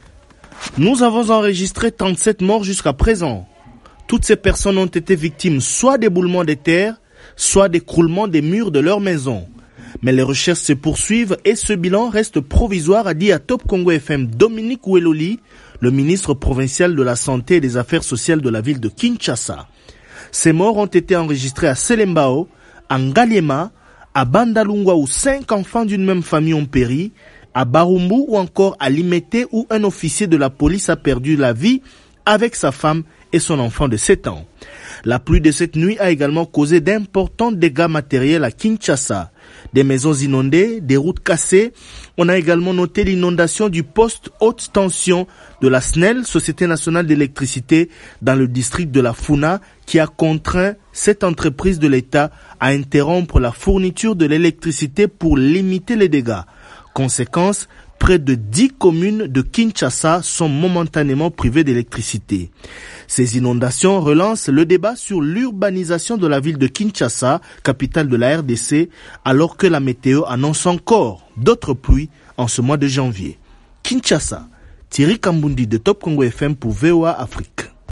Correspondance